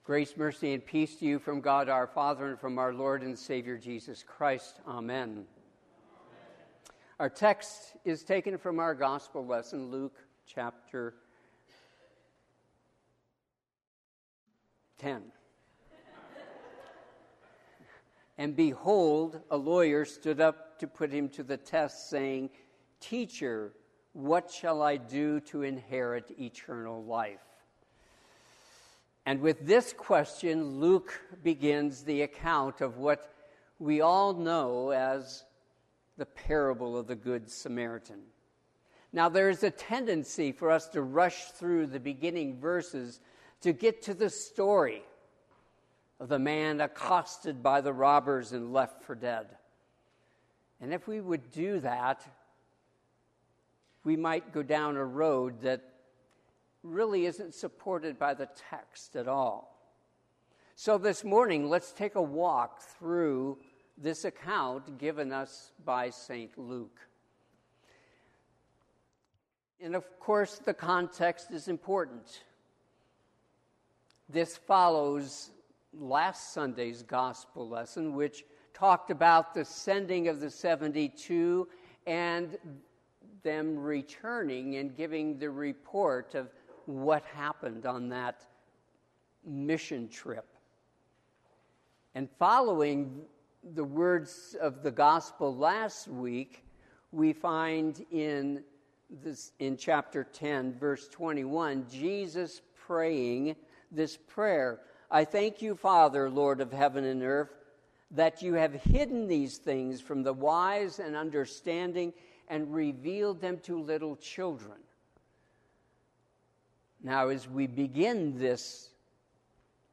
Sermon - 7/10/2022 - Wheat Ridge Lutheran Church, Wheat Ridge, Colorado
Fifth Sunday after Pentecost